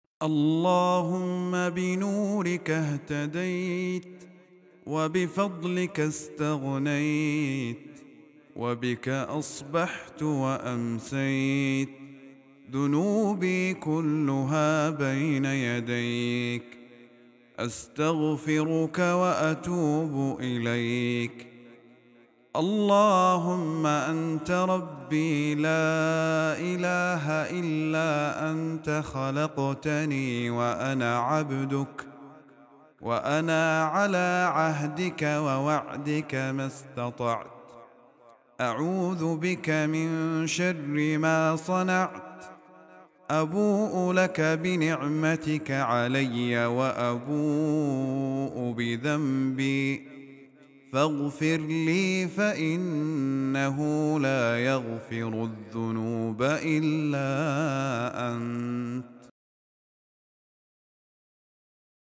دعاء خاشع يعبر عن الافتقار التام إلى الله تعالى والاعتراف بالذنب والتقصير. يحتوي على معاني التوحيد والعبودية والاستغفار، مع التوسل إلى الله بمغفرة الذنوب التي لا يغفرها سواه.